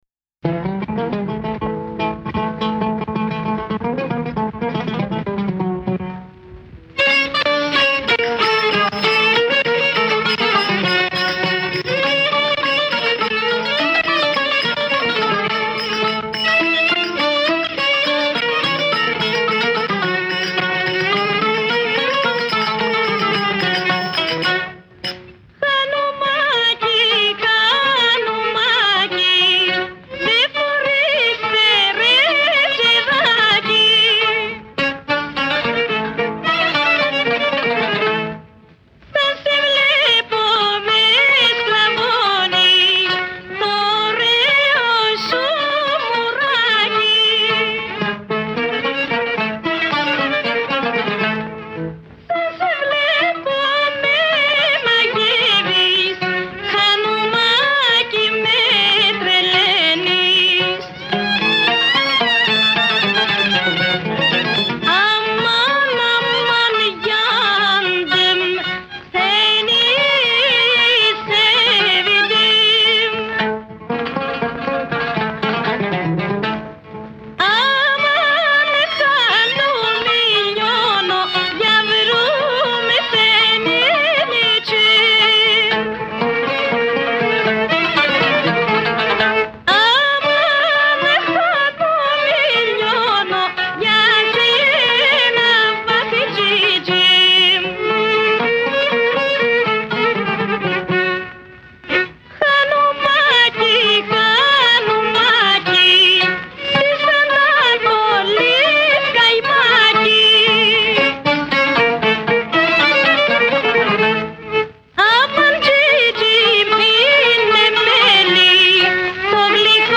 Atenas, 1934